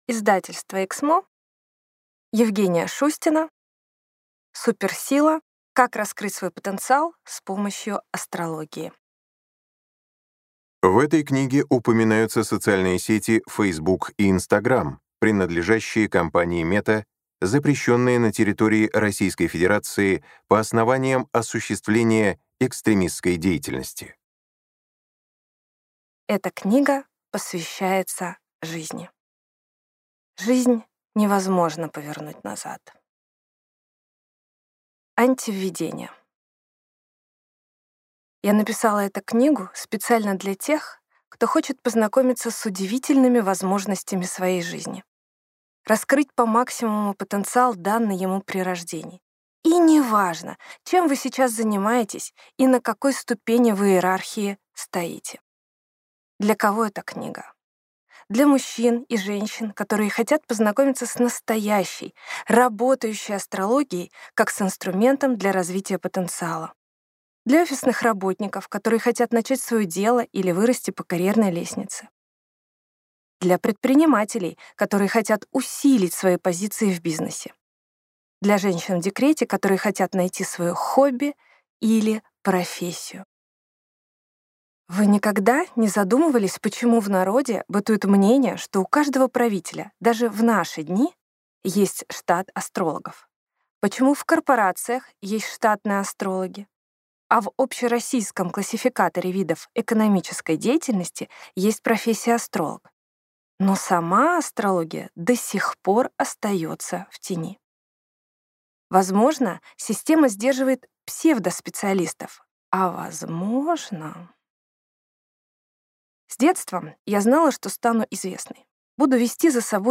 Аудиокнига Суперсила. Как раскрыть свой потенциал с помощью астрологии | Библиотека аудиокниг